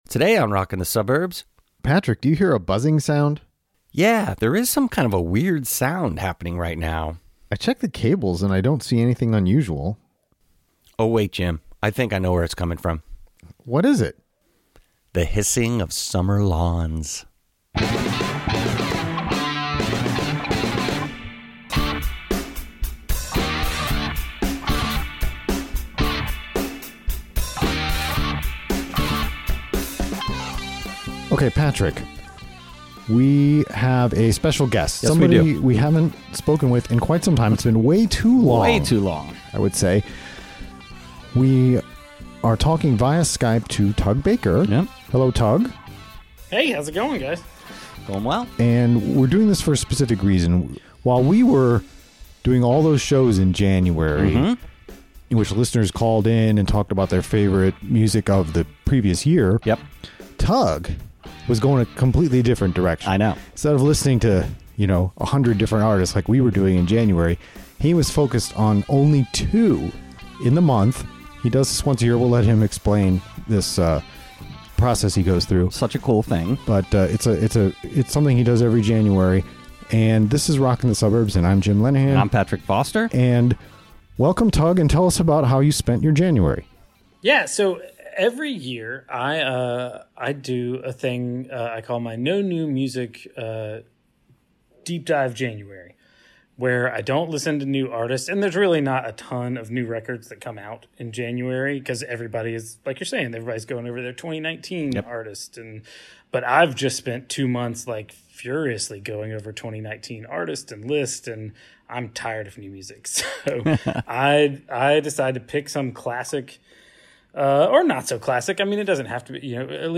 joins via Skype to talk about his annual January tradition of listening exclusively to a couple of classic artists. Today, we learn what he learned about Joni Mitchell.